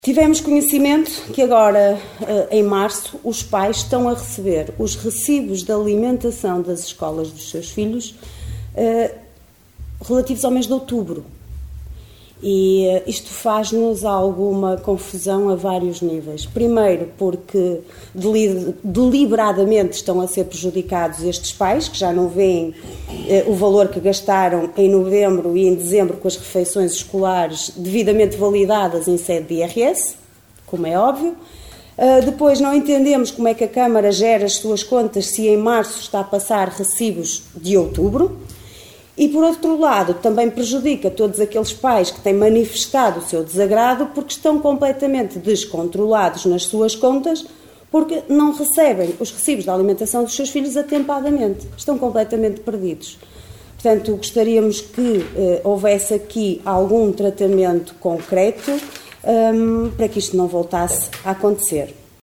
O alerta foi feito pela vereadora da Coligação OCP, Liliana Silva na última reunião do executivo.
Liliana Silva a pedir à Câmara um cuidado especial nesta matéria para que este tipo de situações não se volte a repetir.